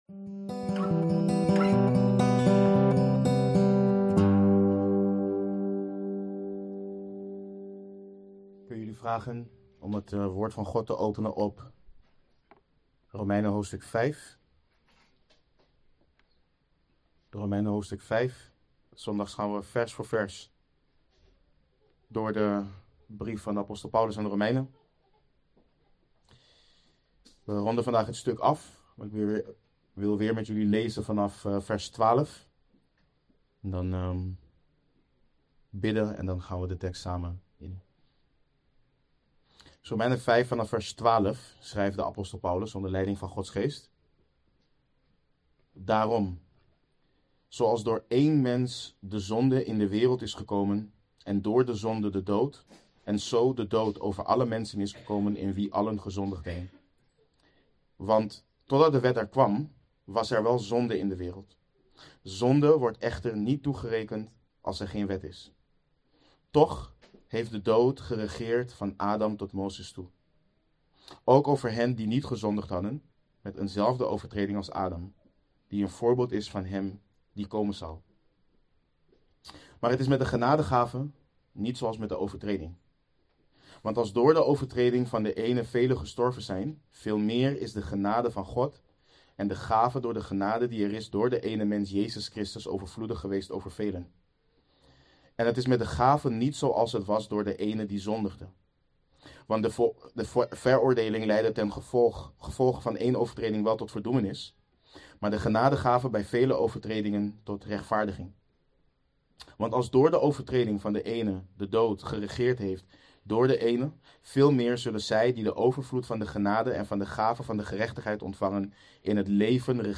Verklarende prediking.